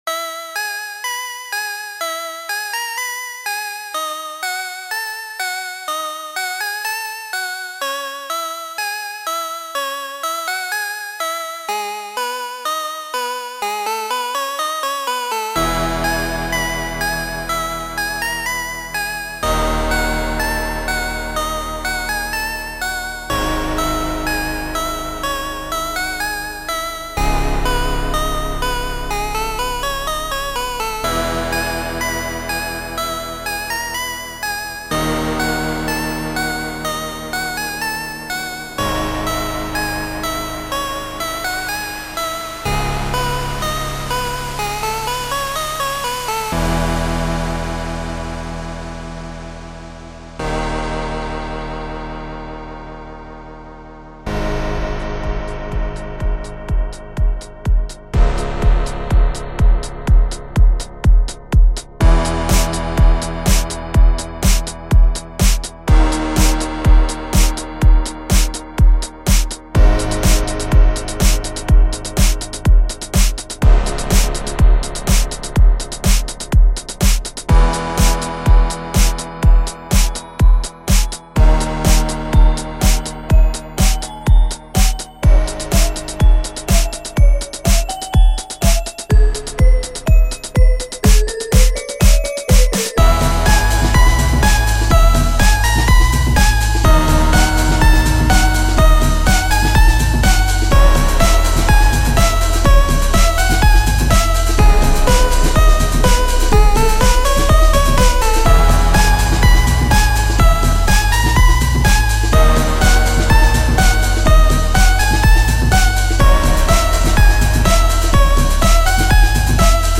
First time I've ever remixed a track!
genre:remix